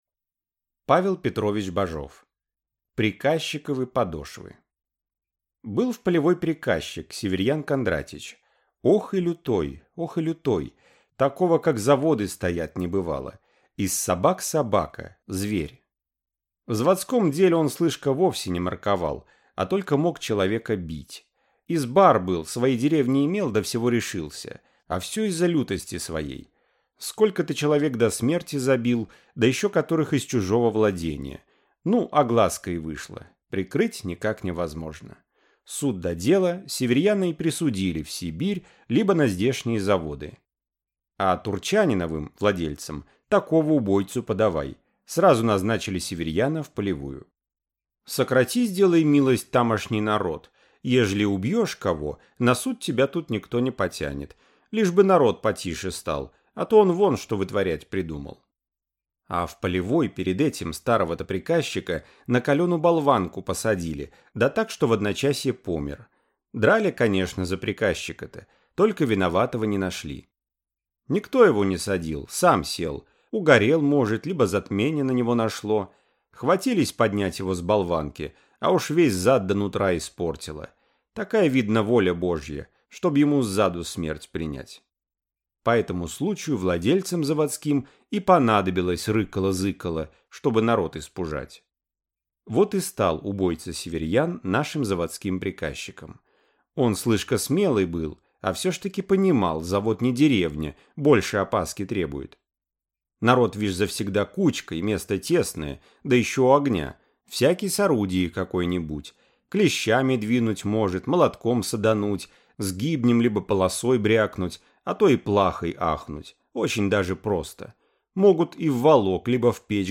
Аудиокнига Приказчиковы подошвы | Библиотека аудиокниг